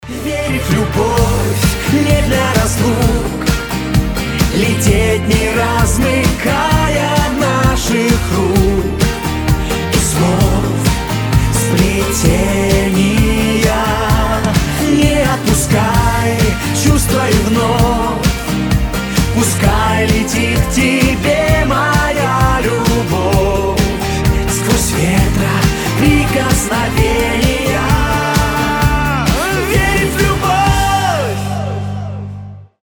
• Качество: 320, Stereo
поп
русский шансон